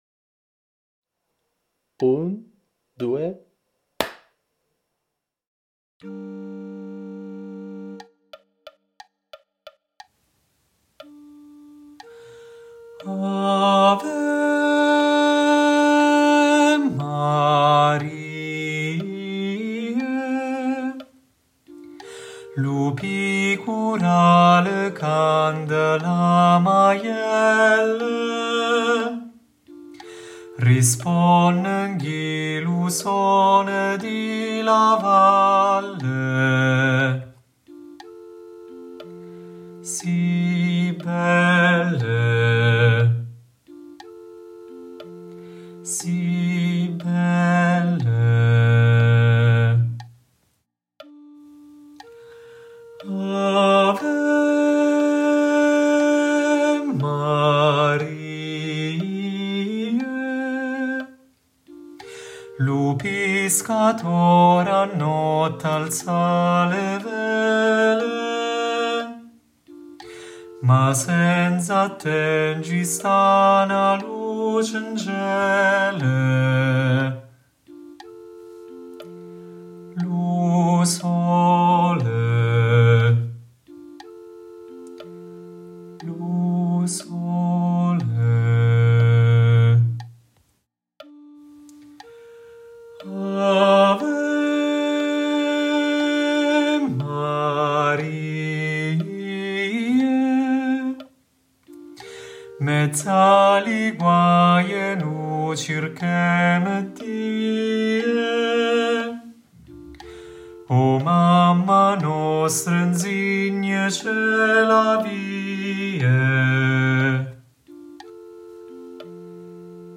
🎧 Voce guida
Bassi
• ⚠ Nella traccia guida sentirai una battuta con il tempo a voce, nel "battere" successivo il ciak di inizio (batti una volta le mani come in un "ciak cinematografico"). Avrai un'altra battuta vuota nella quale sentirai l'intonazione della nota di attacco, una ancora di metronomo e quindi inizierai a cantare.